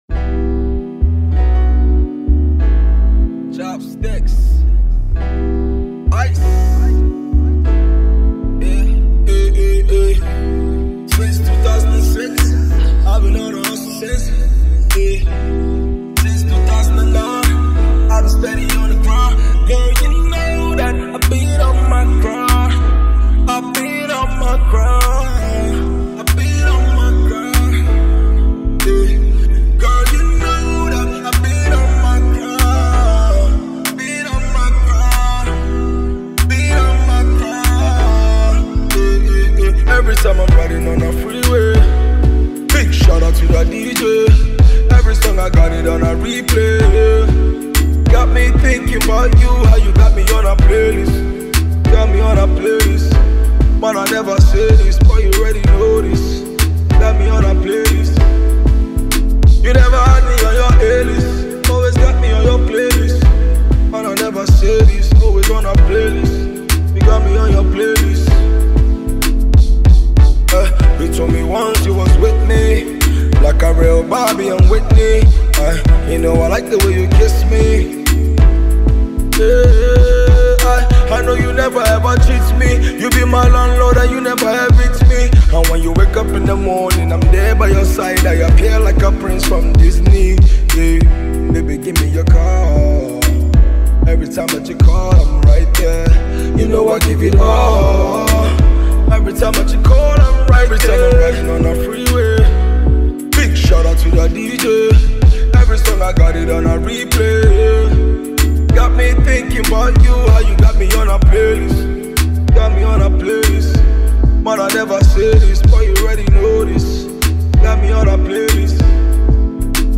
struck a perfect balance between singing and rapping